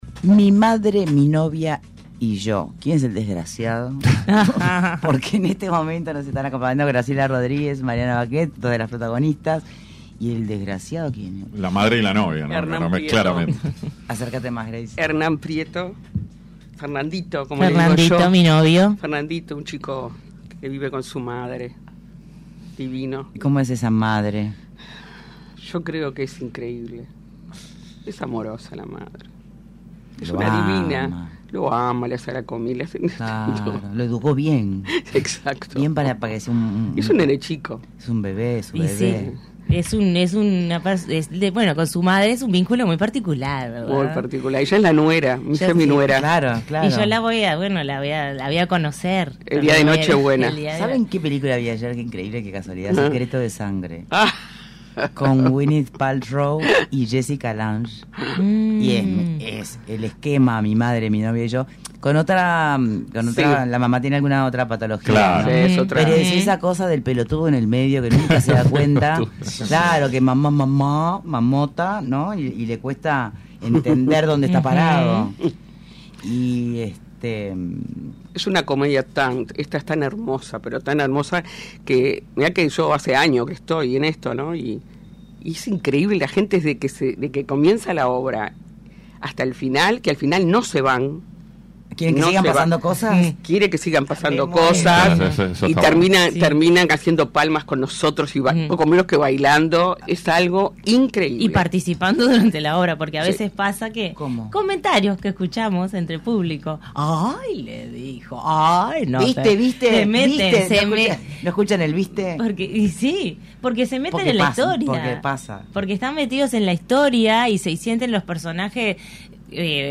Entrevista Mariana Baquet y Graciela Rodríguez
Las reconocidas actrices Graciela Rodríguez y Mariana Baquet visitaron y Punto de Encuentro para hablar de la obra «Mi madre, mi novia y yo». Una comedia dirigida por Juan Luis Granato y escrita por Mechi Bove, que narra el desarraigo del protagonista y el complejo vínculo que mantiene con su madre.